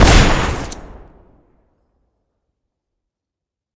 shoot2.ogg